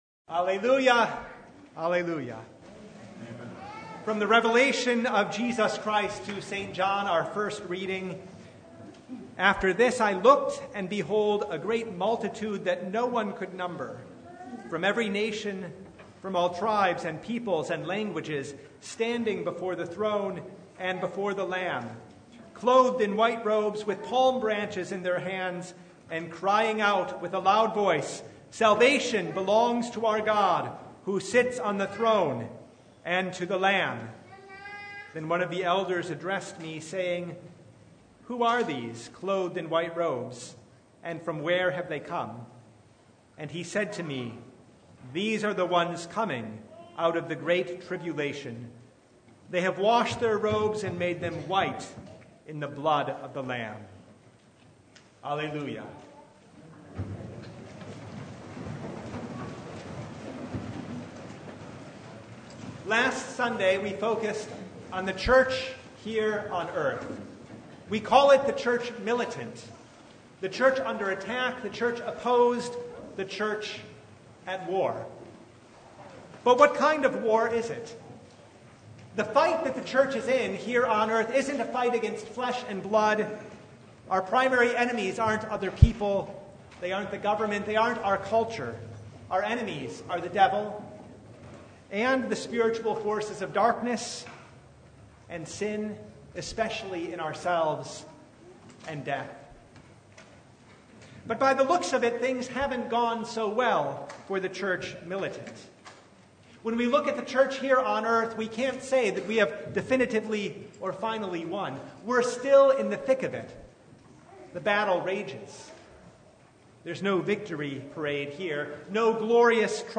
Service Type: The Feast of All Saints' Day
Sermon Only